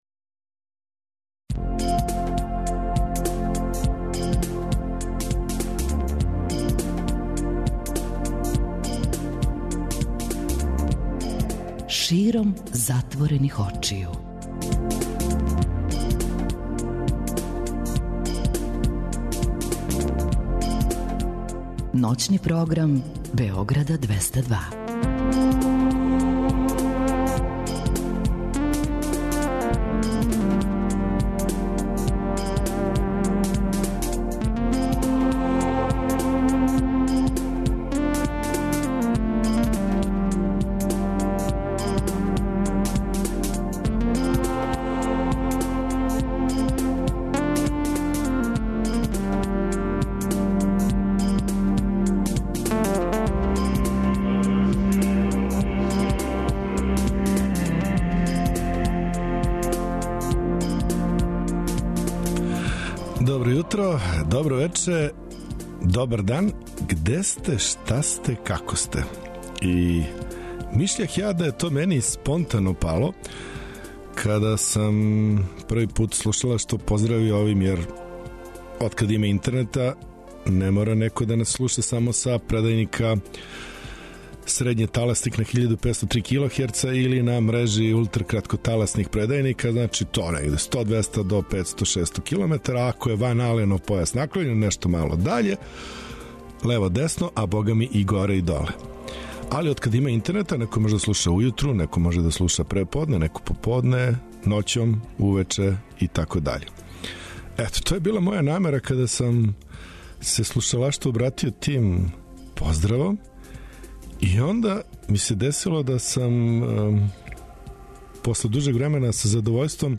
Широм затворених очију, ново издање емисије Златне године, музика 60-тих и 70-тих, када је музика хтела да промени свет, а свет је променио музику.